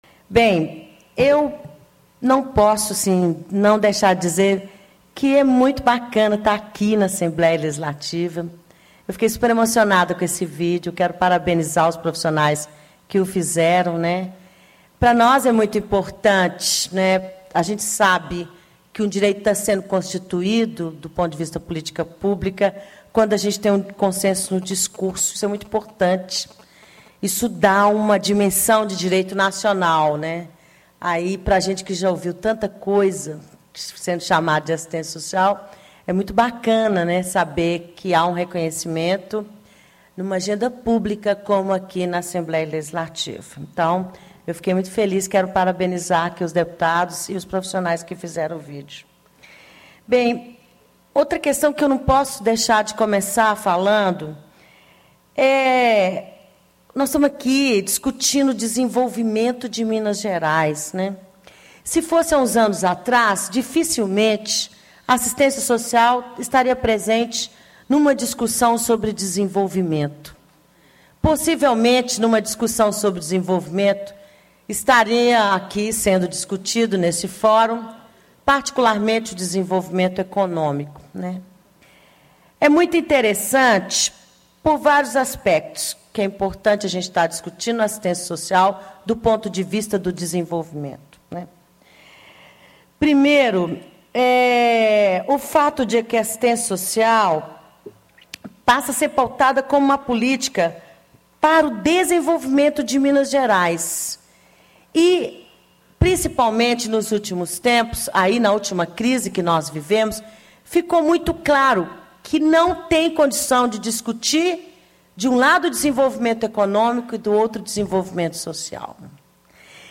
Simone Albuquerque, Diretora do Departamento de Gestão do Sistema Único de Assistência Social do Ministério do Desenvolvimento Social e Combate
Discursos e Palestras